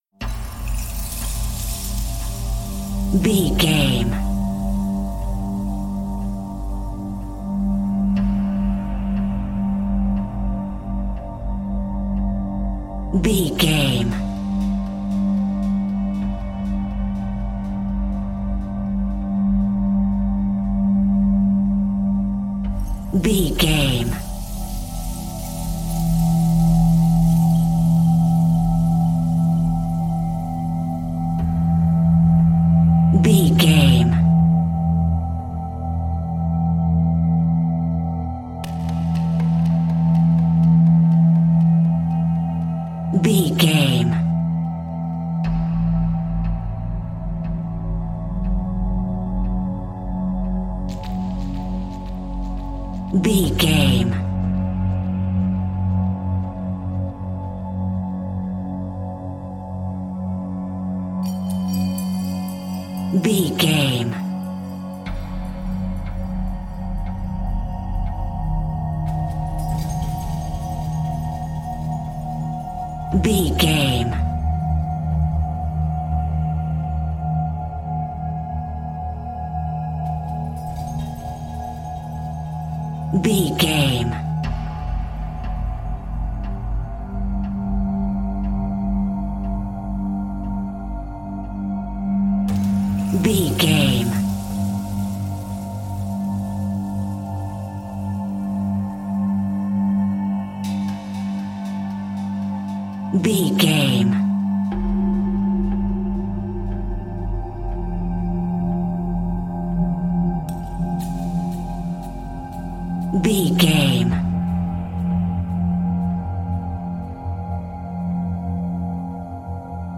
Thriller
Aeolian/Minor
Slow
synthesiser
drum machine
ominous
dark
haunting
creepy